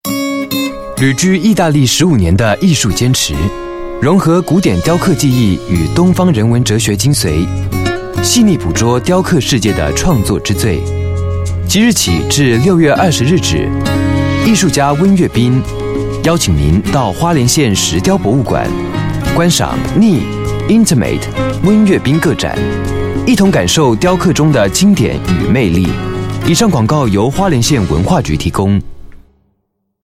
Voice Sample: Voice Sample 01
We use Neumann microphones, Apogee preamps and ProTools HD digital audio workstations for a warm, clean signal path.